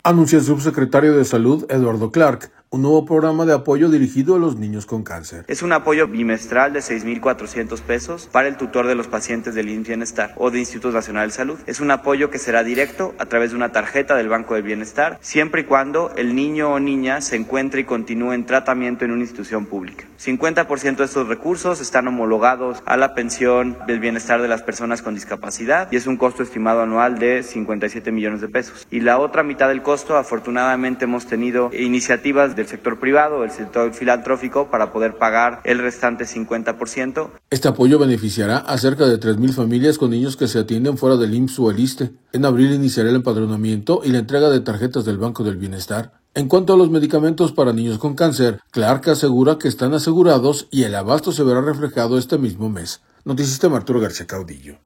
Anuncia el subsecretario de Salud, Eduardo Clark, un nuevo programa de apoyo dirigido a los niños con cáncer.